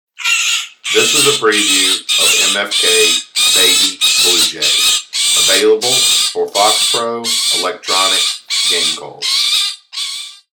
MFK Baby Blue Jay - 16 bit
Recorded with the best professional grade audio equipment MFK strives to produce the highest